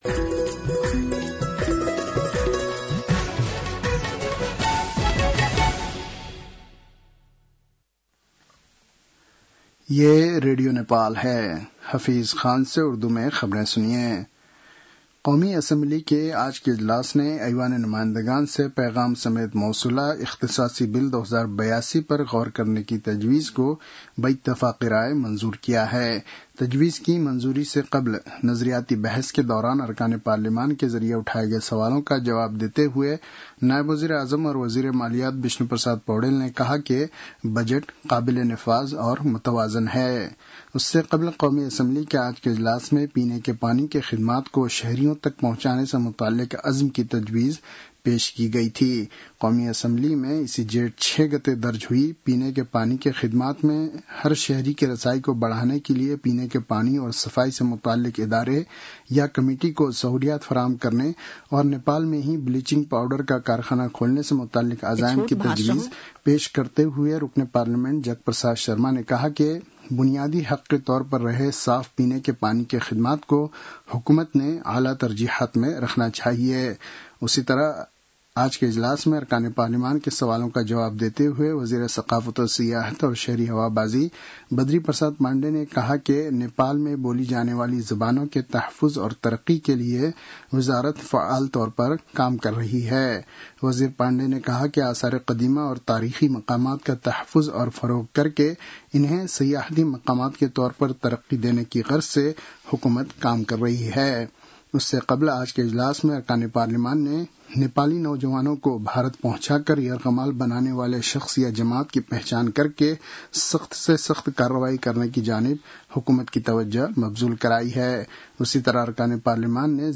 उर्दु भाषामा समाचार : १२ असार , २०८२